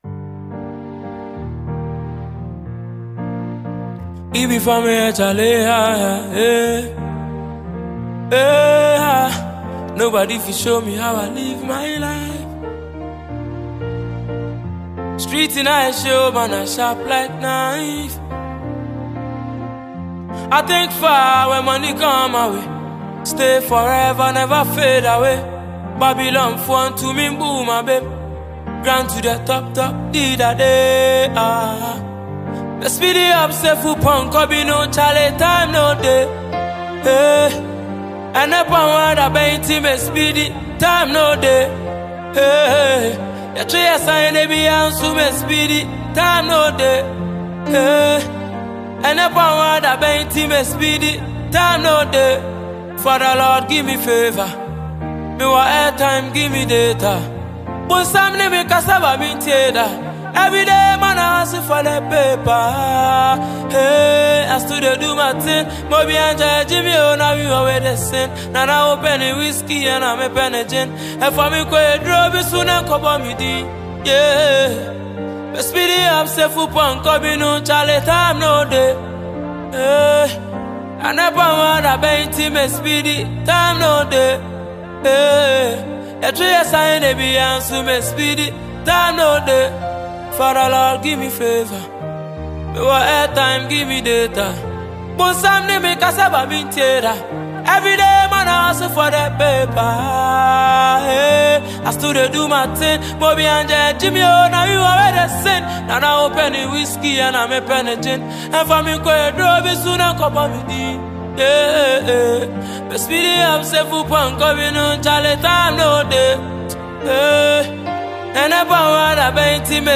Ghanaian silky voice singer